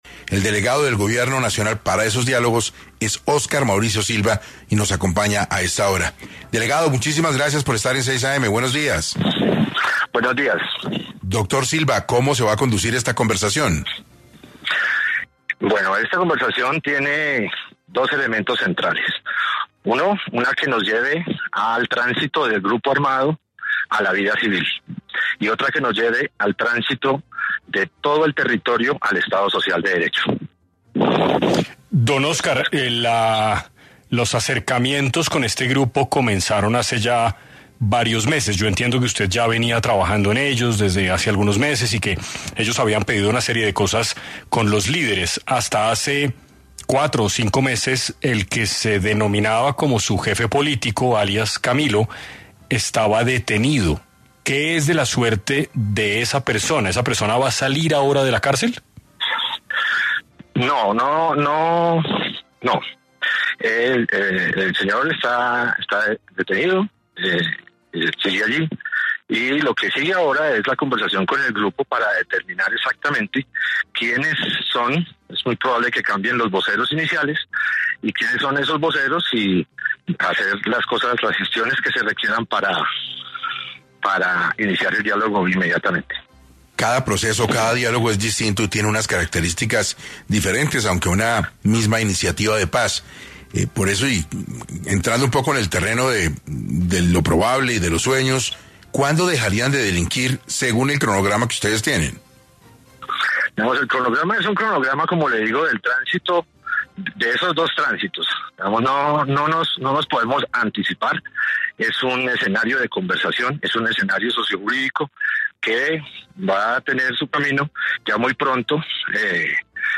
En 6AM habló el delegado del Gobierno Nacional para los diálogos con las Autodefensas de la Sierra Nevada de Santa Marta, Óscar Mauricio Silva, sobre los diálogos anunciados por el presidente Gustavo Petro.